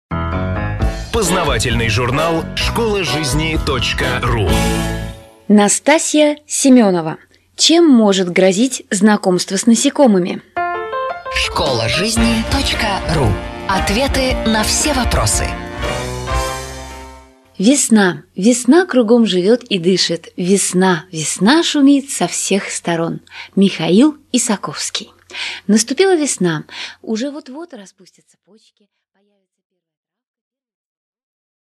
Аудиокнига Анафилактический шок от укуса, или Чем может грозить знакомство с насекомыми?